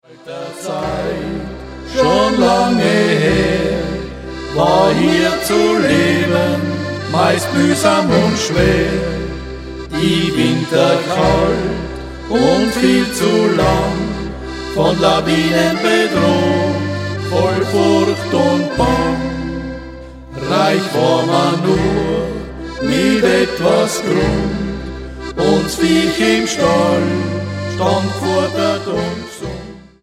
Genre: Volkstümliche Musik
Akkordeon, Gesang
Gitarre, Gesang
Kontrabass, Gesang